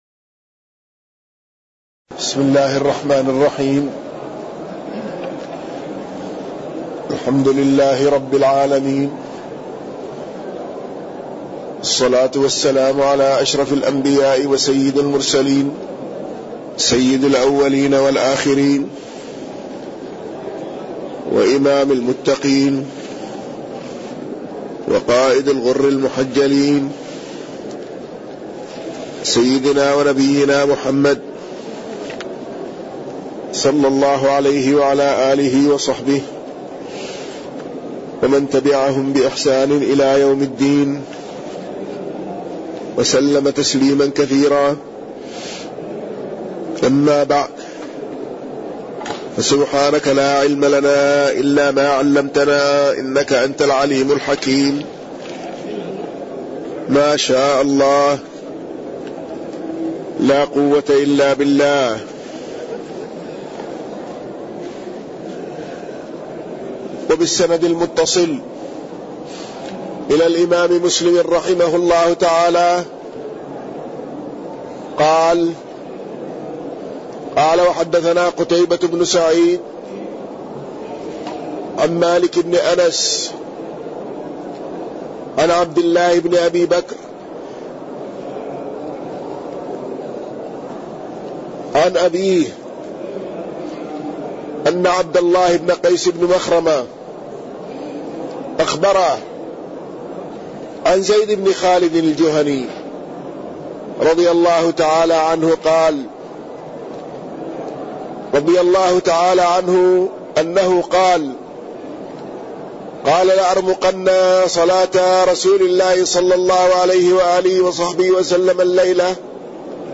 تاريخ النشر ٢٥ محرم ١٤٣١ هـ المكان: المسجد النبوي الشيخ